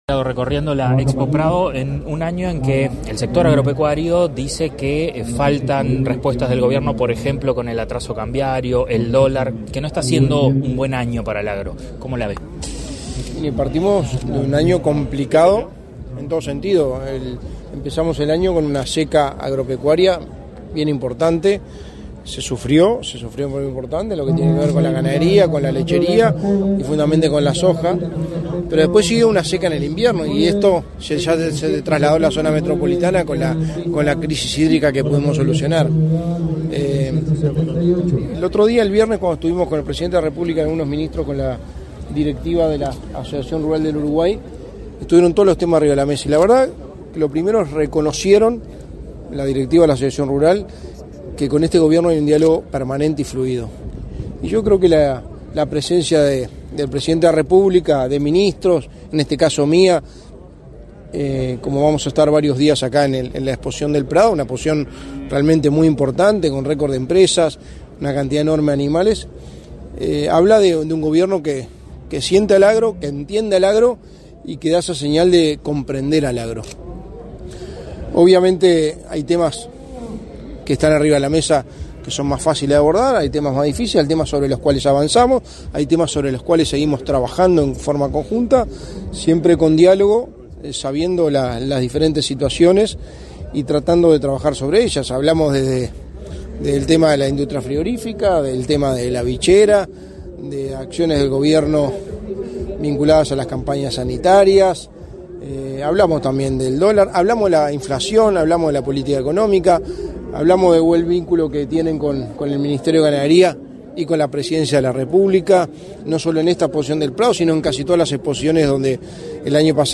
Declaraciones de prensa del secretario de la Presidencia, Álvaro Delgado
Tras el evento, Delgado realizó declaraciones a la prensa.